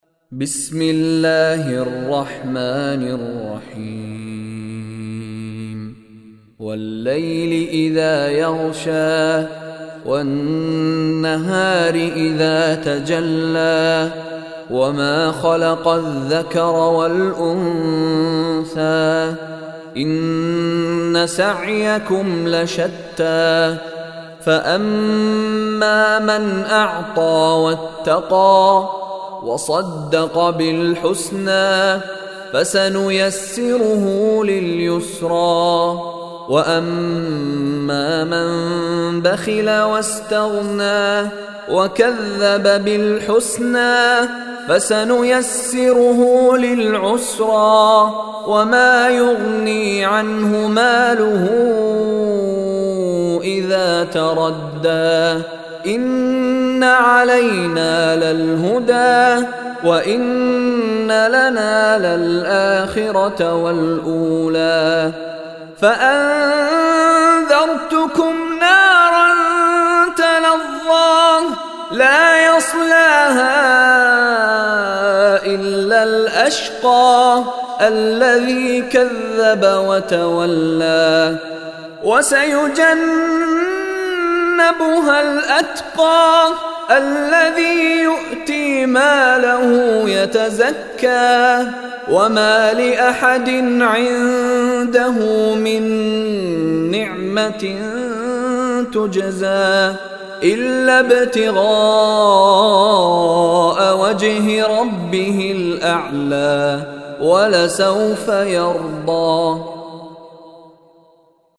Surah Lail MP3 Recitation by Mishary Rashid
Surah Lail is 92 chapter / Surah of Holy Quran. Listen online and download beautiful Quran tilawat / recitation of Surah Layl in the beautiful voice of Sheikh Mishary Rashid Alafasy.